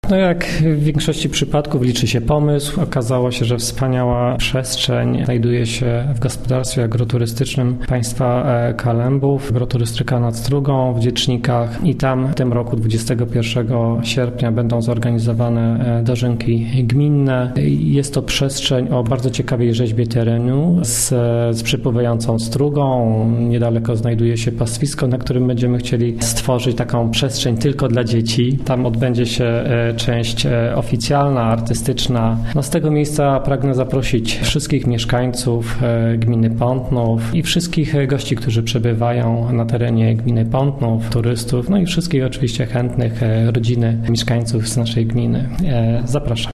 – mówił wójt gminy Pątnów Jacek Olczyk.